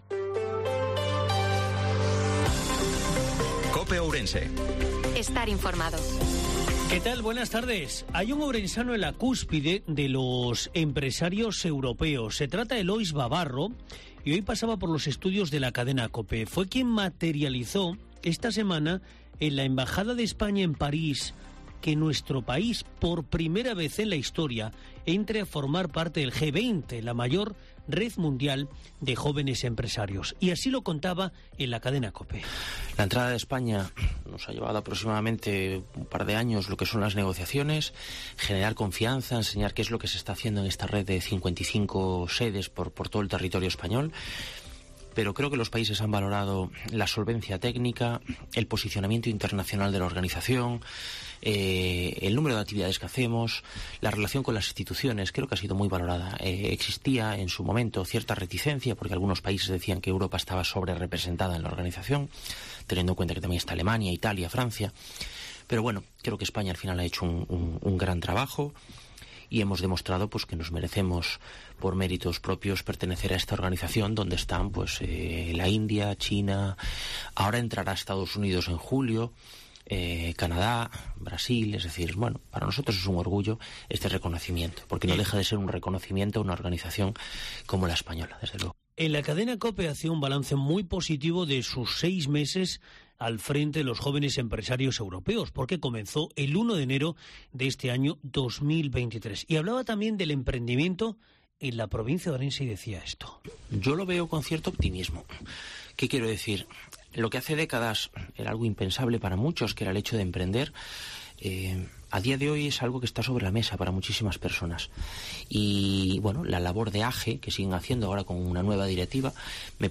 INFORMATIVO MEDIODIA COPE OURENSE-09/06/2023